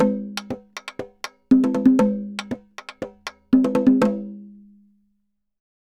Tambora_Merengue 120_2.wav